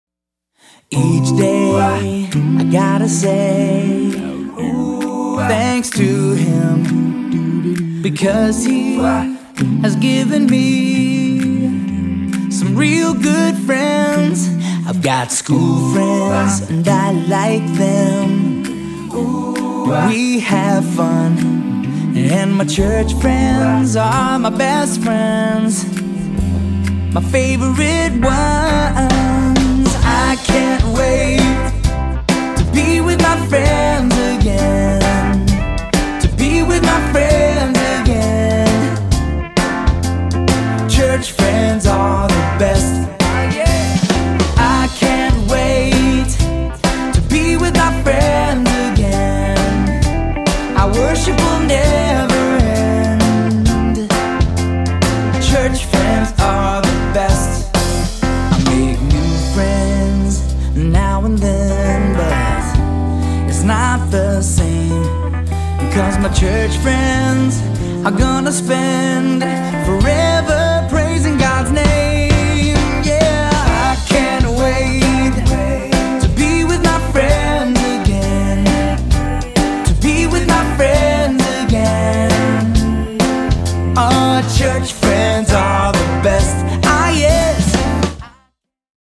energetic praise and worship music